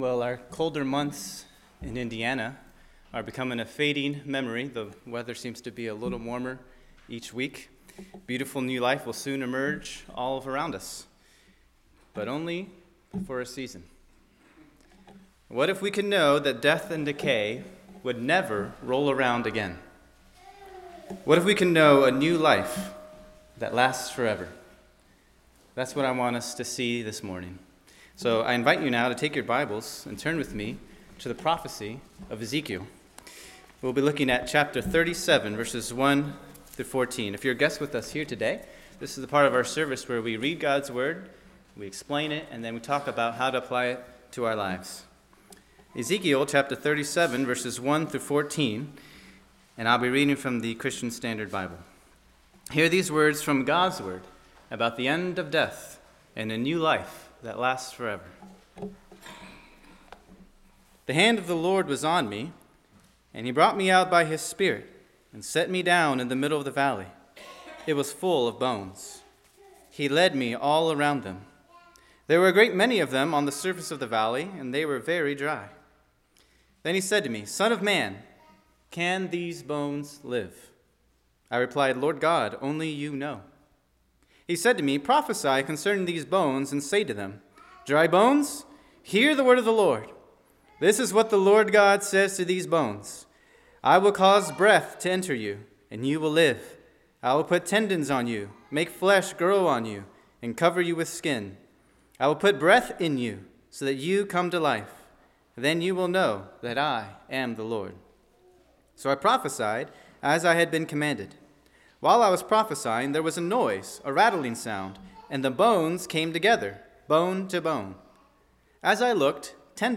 Sermon preached